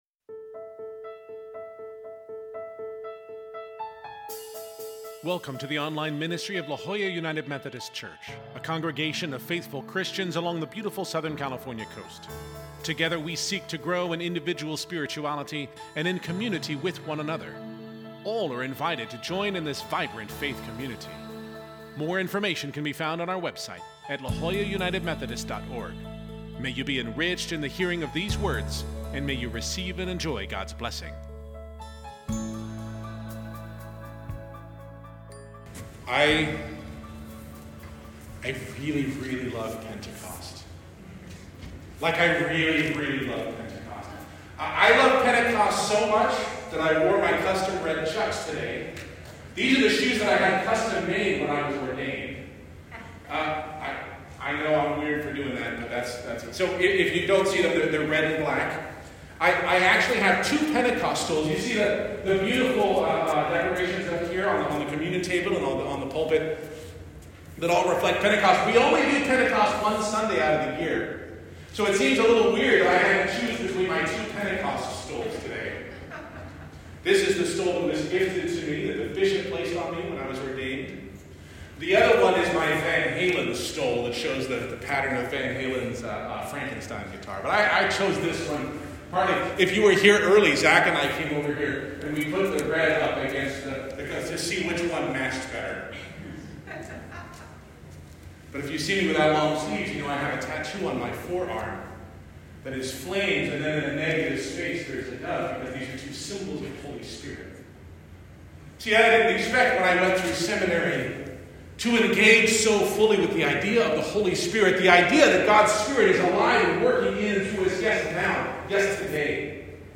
Note: The audio for the first half of the service suffers from technical issues during the livestream and is very quiet.